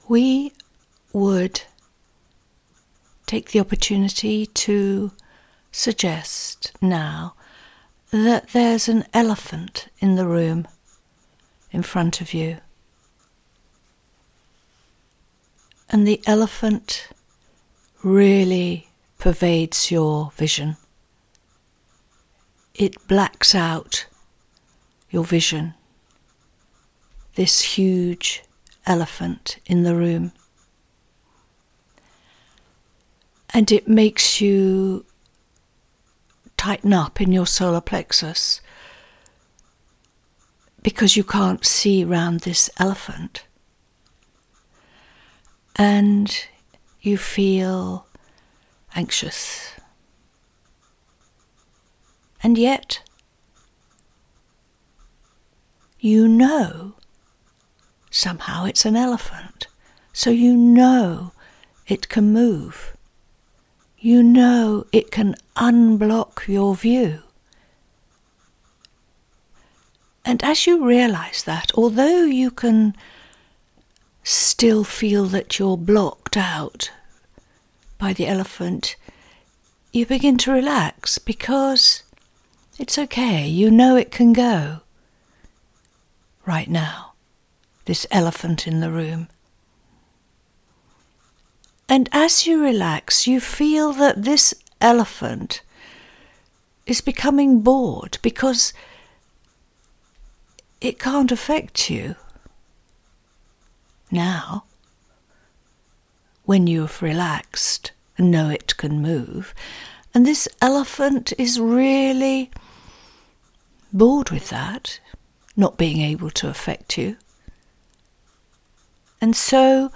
audio meditation